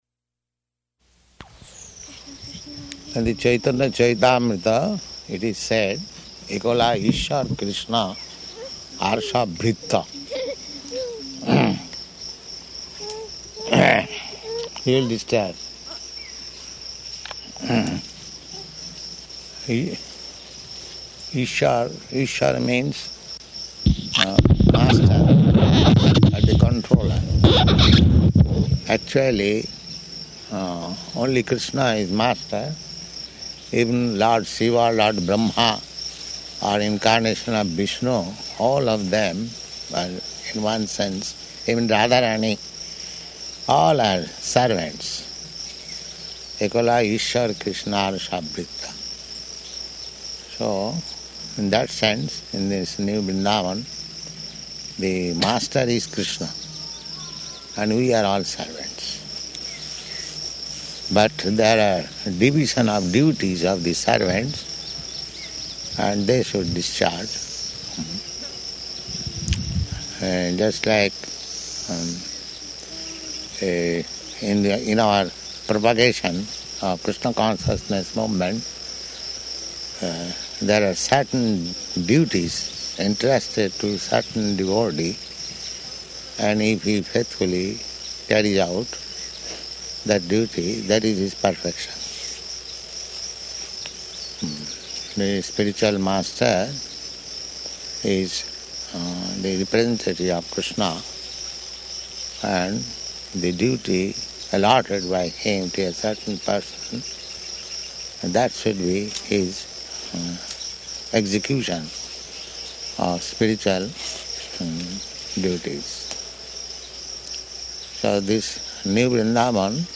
Type: Conversation
Location: New Vrindavan
[baby making noises] [aside:] He will disturb.